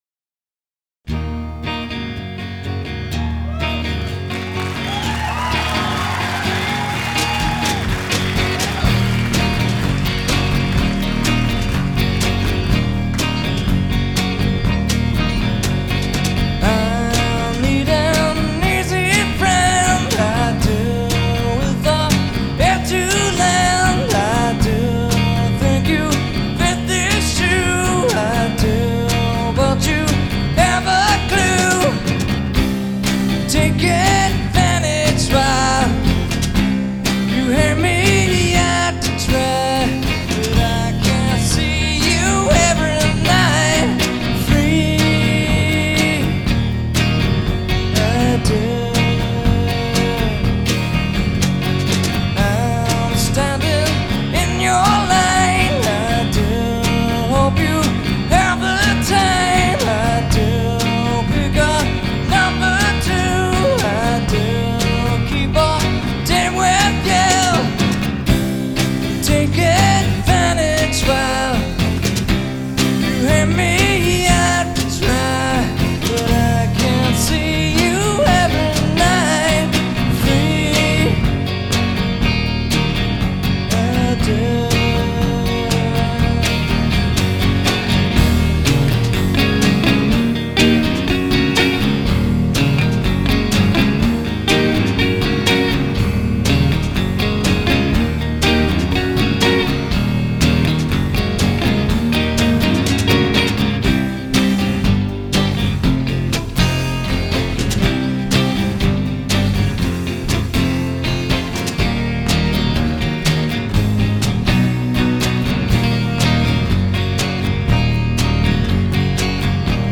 Grunge, Acoustic Rock, Alternative Rock